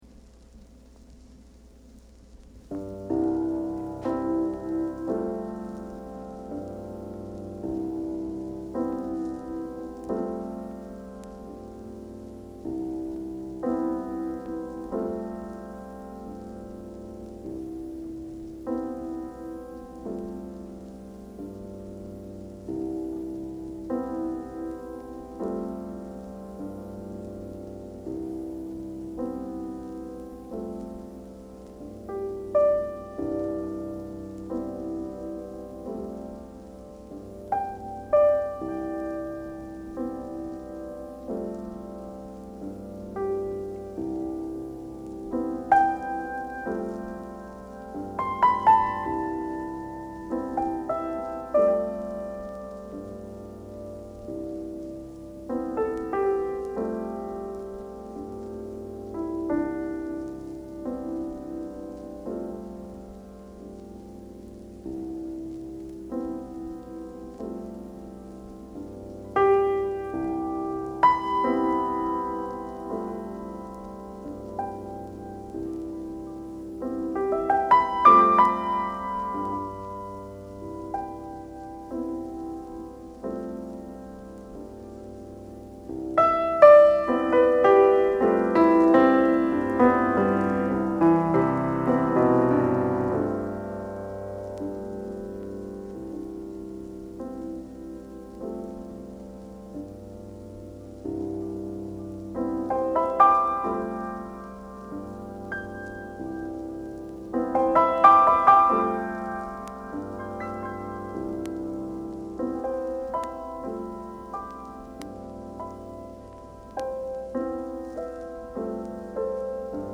Hard Bop / Piano Jazz Jacket: 表面左上に軽いシミあり 1958年12月、ニューヨーク録音。
6分超の無伴奏ソロ。一つのコードの上で音が湧き続ける、他に類のない演奏。